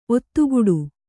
♪ ottuguḍu